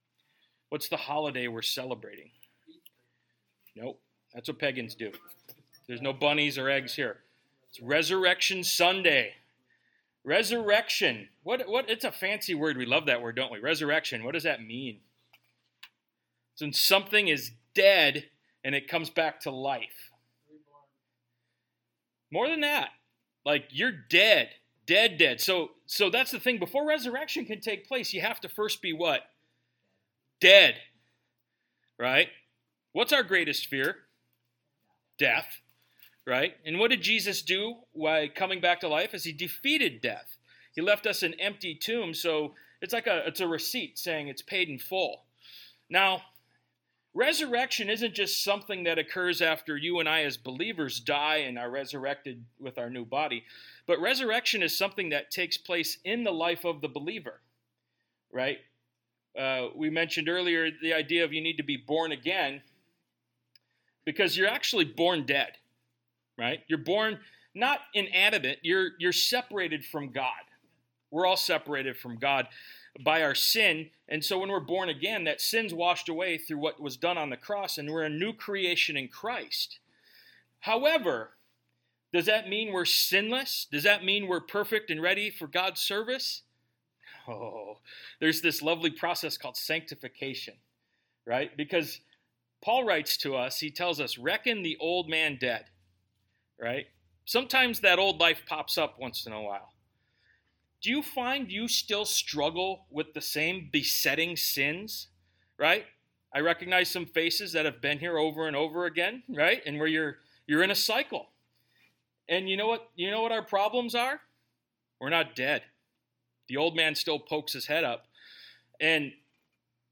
Calvary Chapel Keweenaw Peninsula